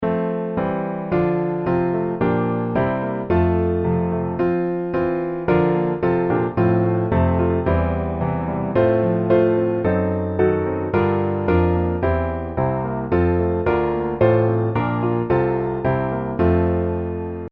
Piano Hymns
F大調